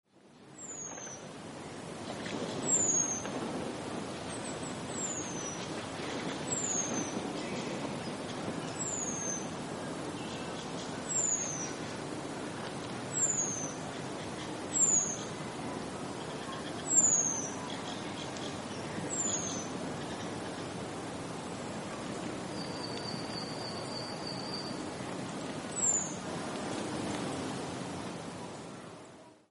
Common Blackbird -Turdus merula
Call 3: Soft tseer call
Com_Blackbird_3_tseer.mp3